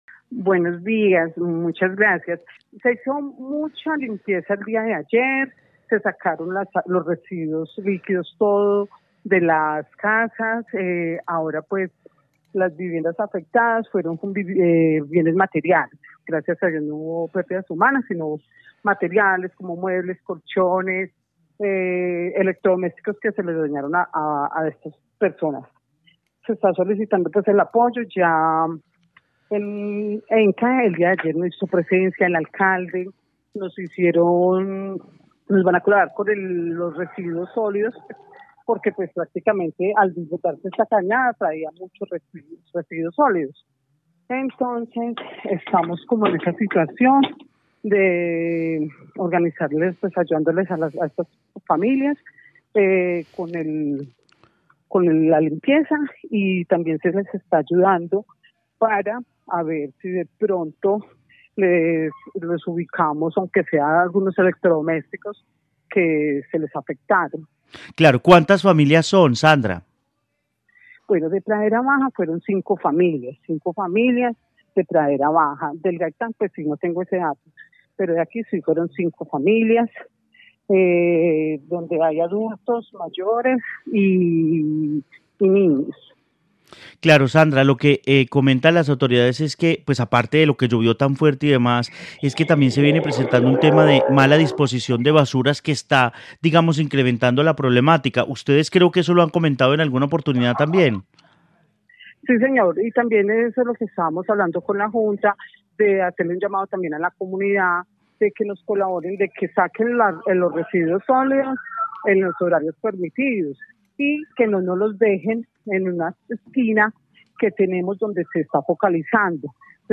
En el noticiero de mediodía de Caracol Radio Armenia hablamos con